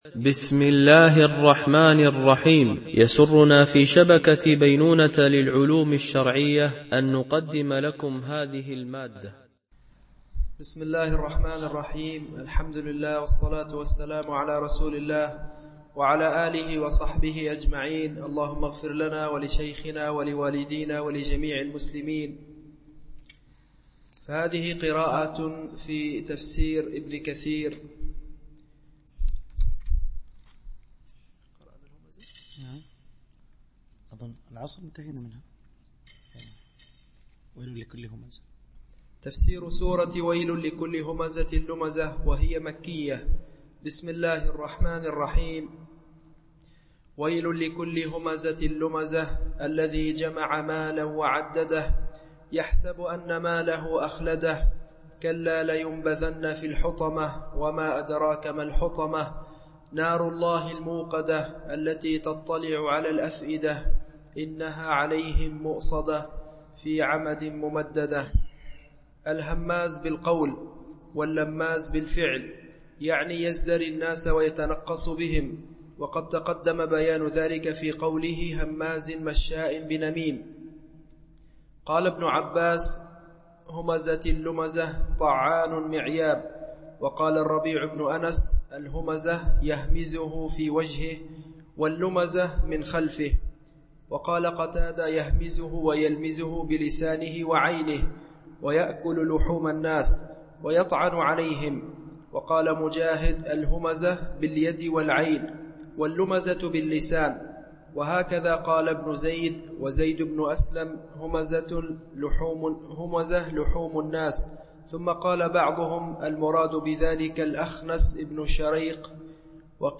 شرح مختصر تفسير ابن كثير(عمدة التفسير) الدرس 60 (سورة الهمزة والفيل وقريش)
MP3 Mono 22kHz 32Kbps (CBR)